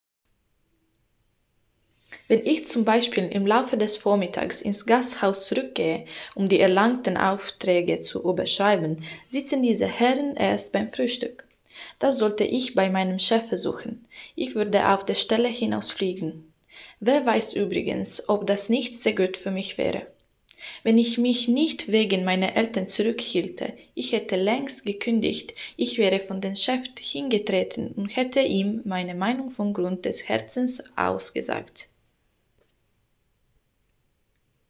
Language Reels
German level: high